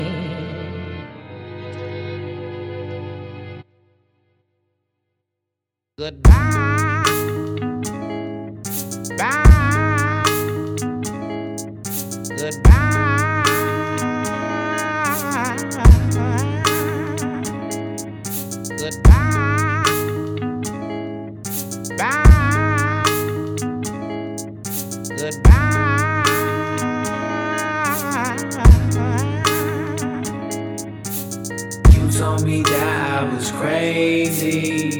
Жанр: Хип-Хоп / Рэп / R&B / Соул
Hip-Hop, Rap, R&B, Soul